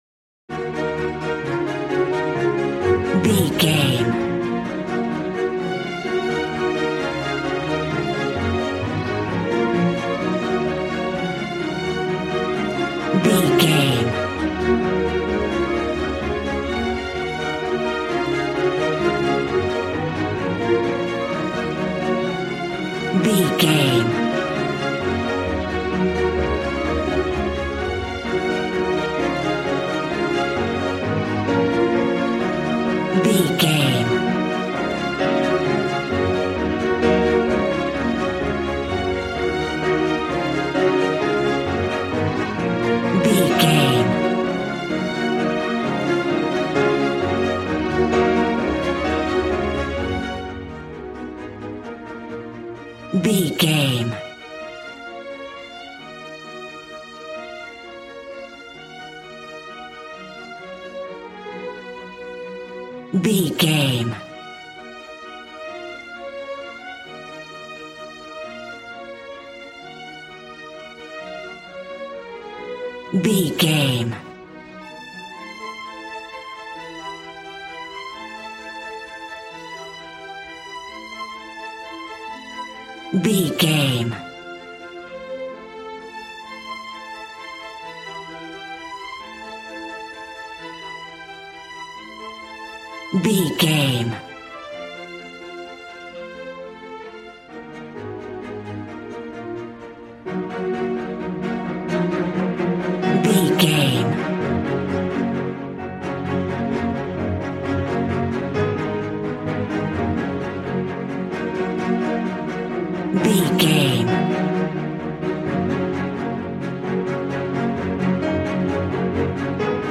Regal and romantic, a classy piece of classical music.
Ionian/Major
G♭
cello
violin
strings